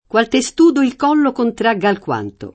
testudine [teSt2dine] s. f. — voce lett. per «testuggine», spec. in alcuni sign. figurati — poet. testudo [teSt2do]: qual testudo il collo Contragga alquanto [